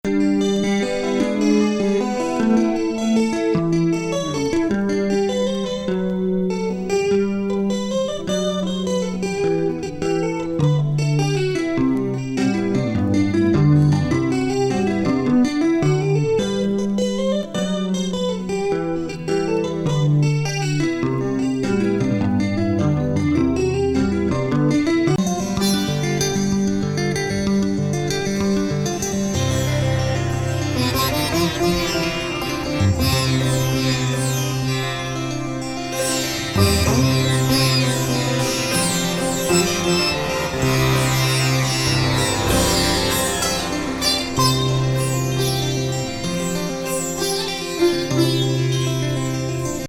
非常に芳醇なアンダルシア・ムード
漂う昇天級ギター・インスト・アルバム！